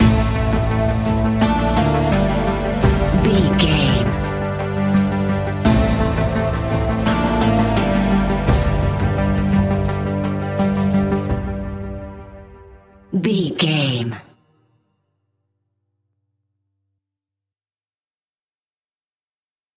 In-crescendo
Thriller
Aeolian/Minor
ominous
dark
haunting
eerie
instrumentals
horror music
Horror Pads
horror piano
Horror Synths